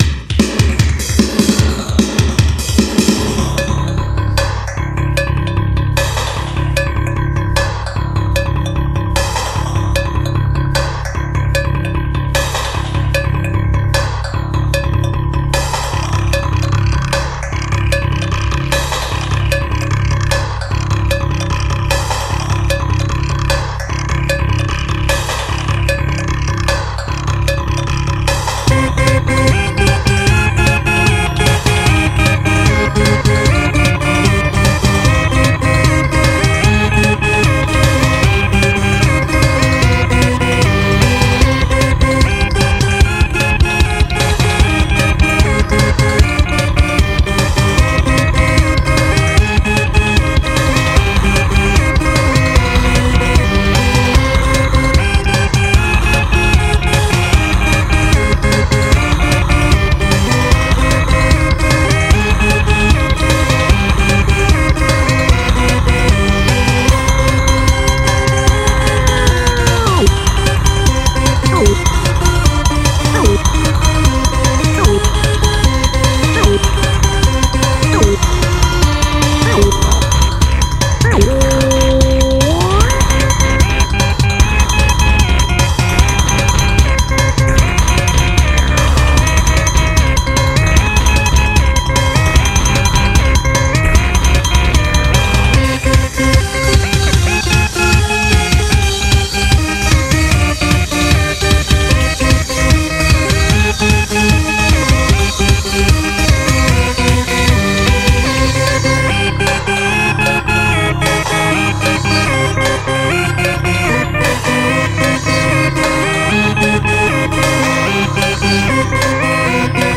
and is basically a live jam
keyboards
The track is heavily and obviously influenced by Baroque music, which is something I have had a great affinity with since A-level music, where we harmonised Bach corales on a weekly basis.